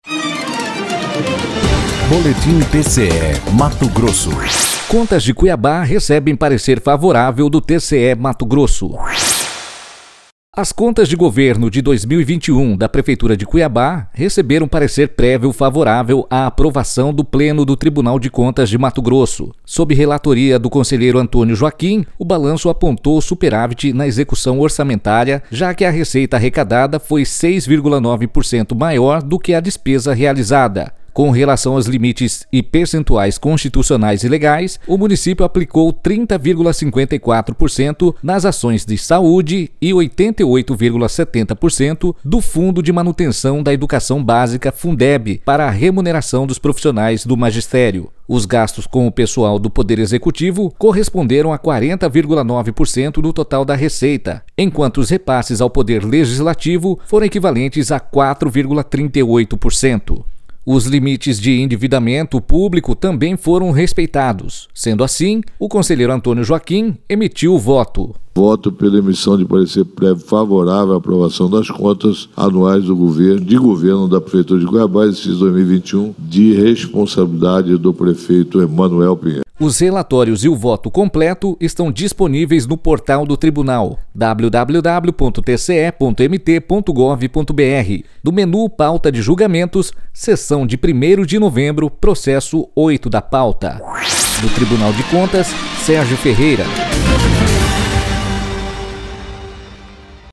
Sonora: Antonio Joaquim – conselheiro do TCE-MT